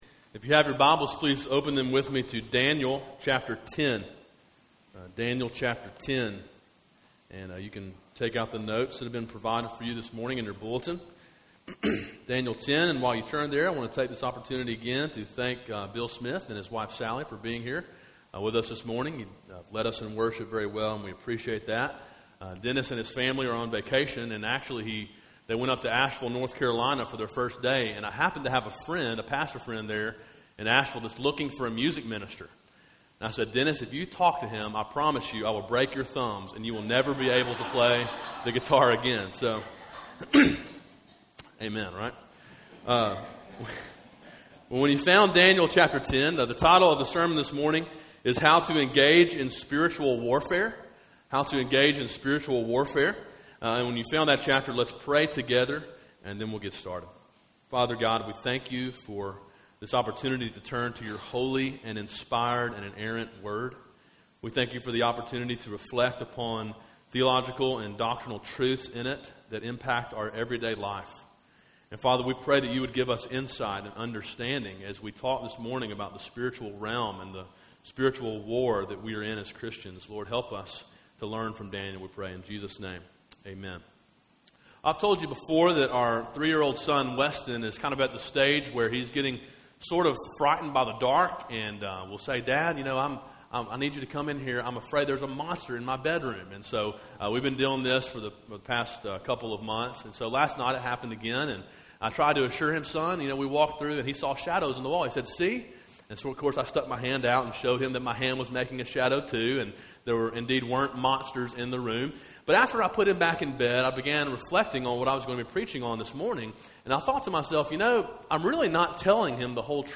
A sermon in a series on the book of Daniel.